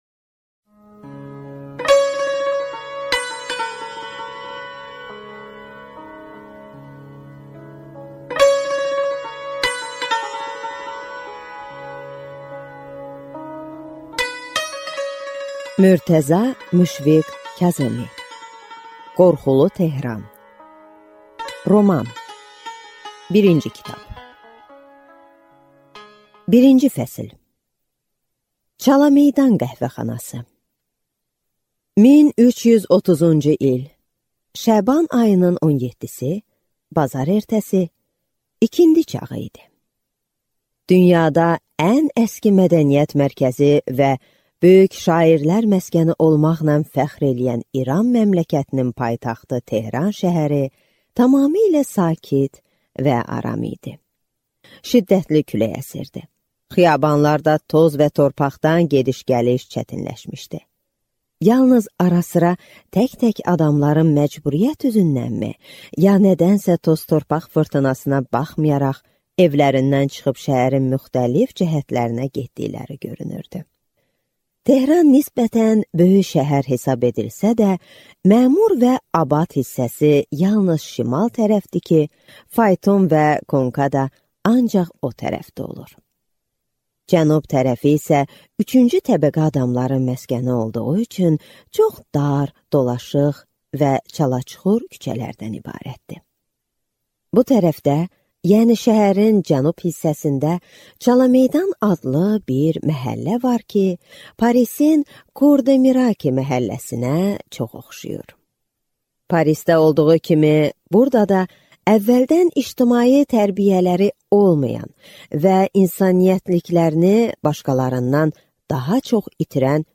Аудиокнига Qorxulu Tehran - I kitab | Библиотека аудиокниг